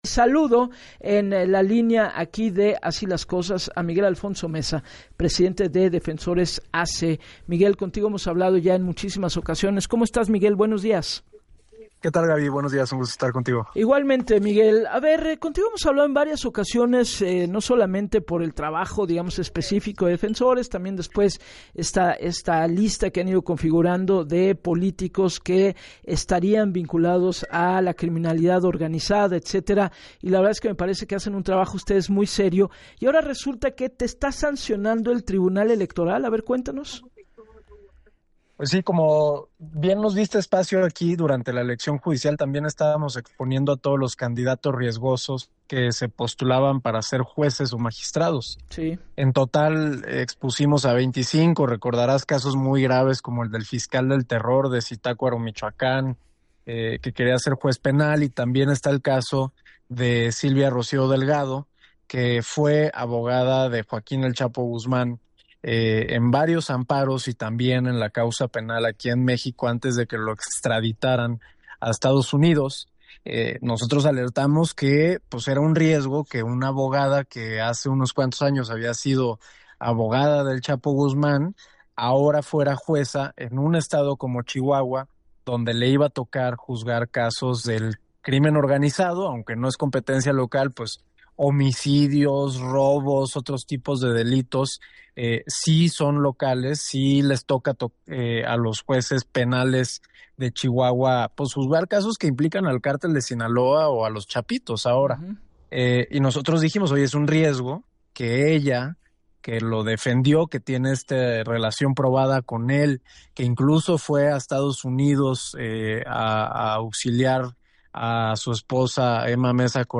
En entrevista para “Así las Cosas” con Gabriela Warkentin el abogado recordó la advertencia lanzada sobre el riesgo que representaba para la sociedad el tener a un juez o magistrado vinculado con el narcotráfico